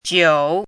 怎么读
jiǔ
酒 [jiǔ]